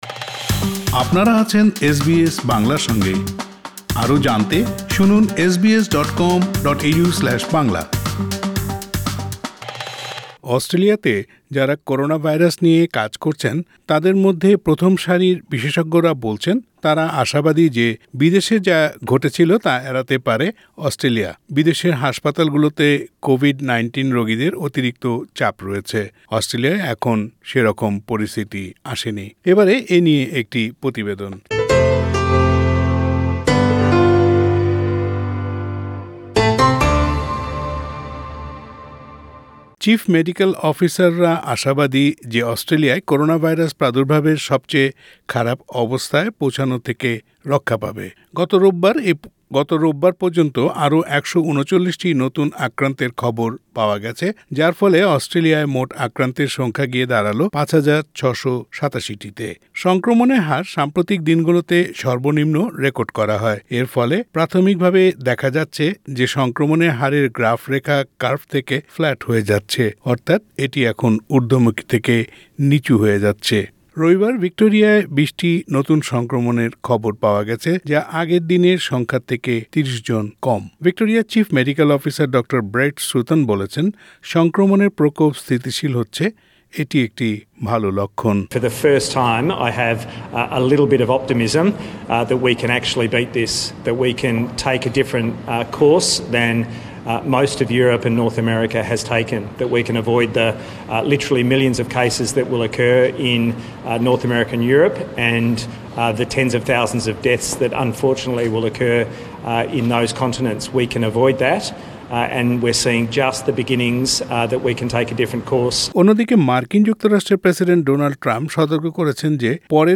প্রতিবেদনটি শুনতে উপরের অডিও প্লেয়ারে ক্লিক করুন।